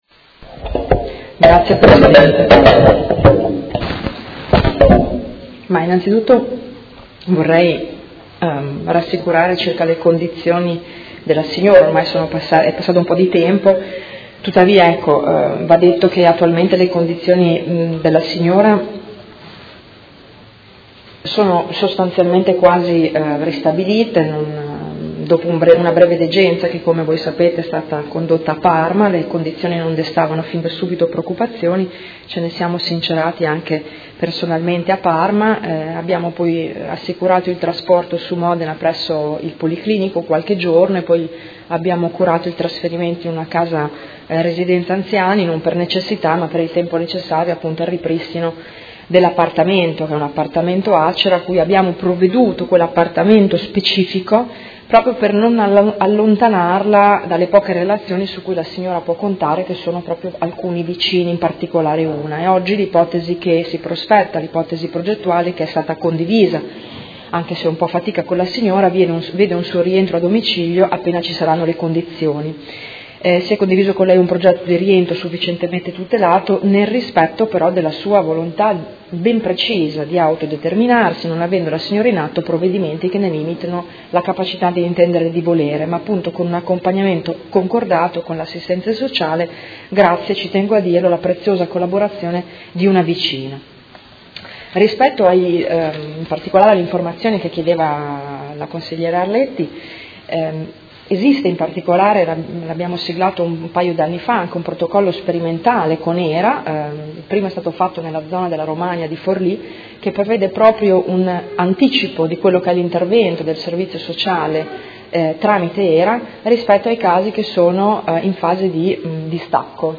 Seduta del 20/07/2017 Risponde. Interrogazione dei Consiglieri Arletti e Fasano (PD) avente per oggetto: Quali sono le condizioni di salute della donna anziana ospite di alloggio Acer recentemente ustionatasi dopo il taglio delle utenze gas?